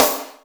Snares
SNARE_12.WAV